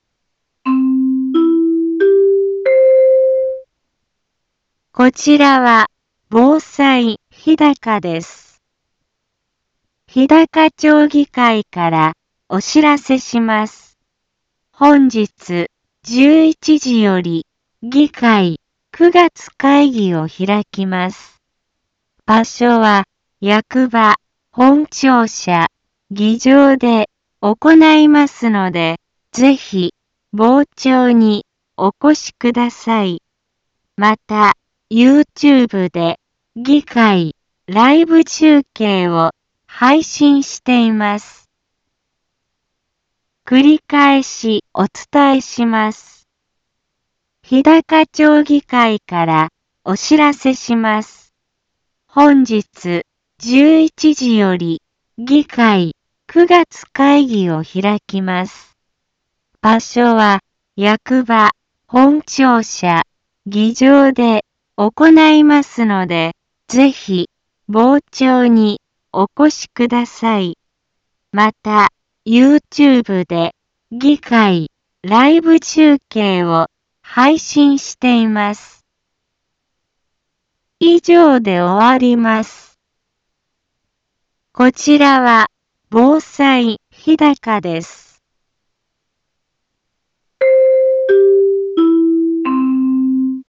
一般放送情報